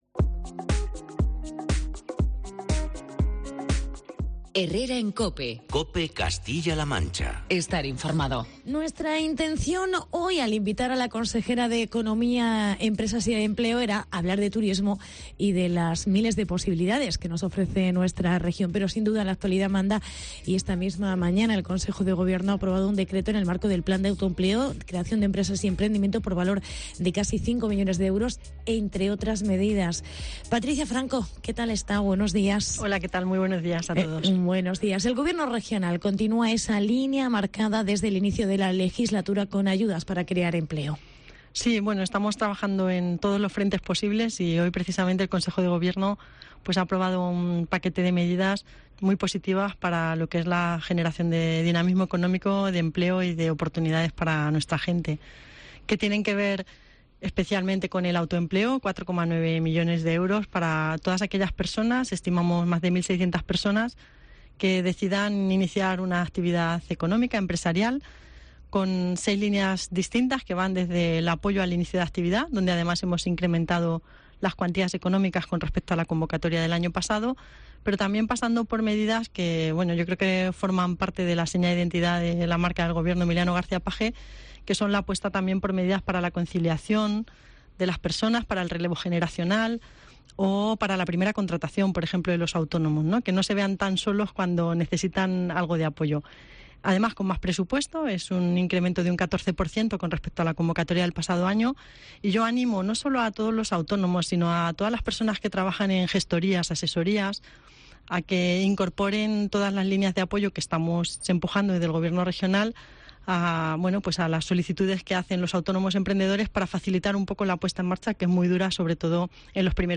Entrevista con la Consejera de Empleo. Patricia Franco